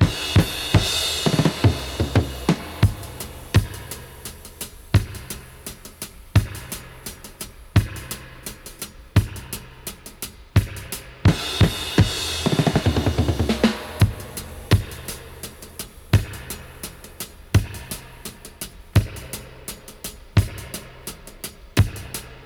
85-DUB-03.wav